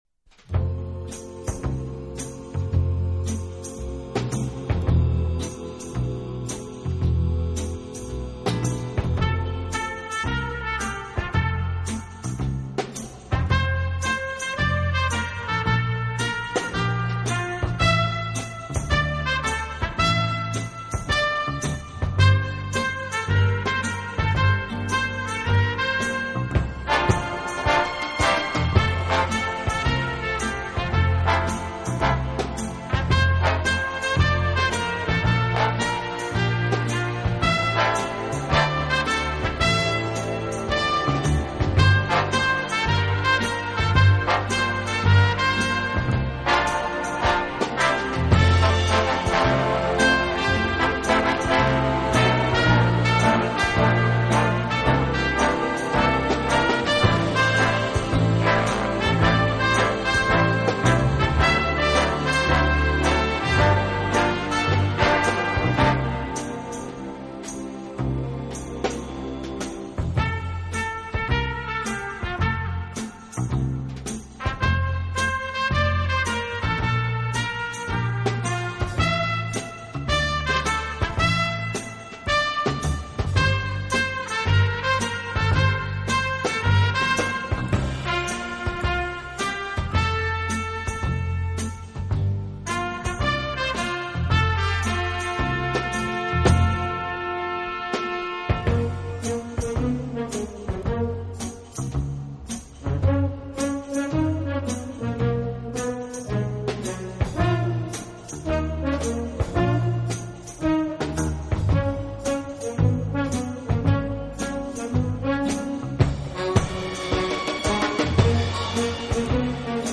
【轻音乐】
小号的音色，让他演奏主旋律，而由弦乐器予以衬托铺垫，音乐风格迷人柔情，声情并
温情、柔软、浪漫是他的特色，也是他与德国众艺术家不同的地方。